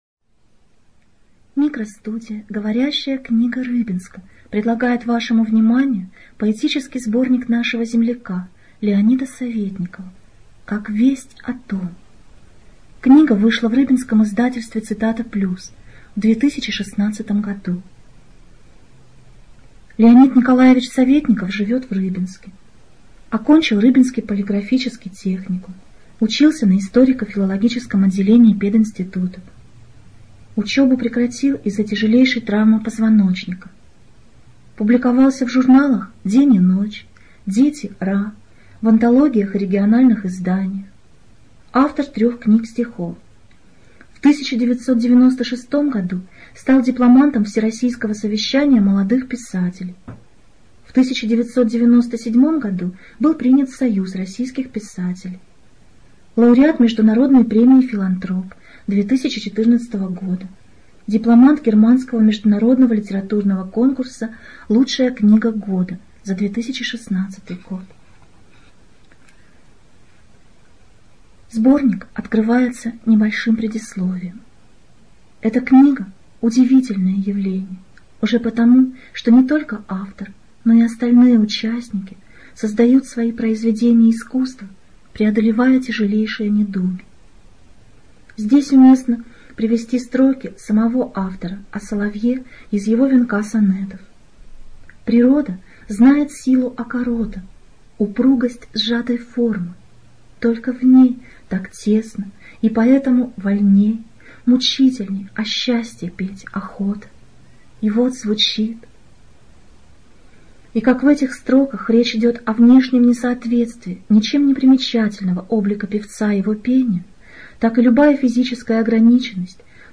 ЖанрПоэзия
Студия звукозаписиГоворящая книга Рыбинска